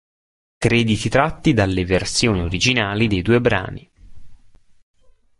/ˈdal.le/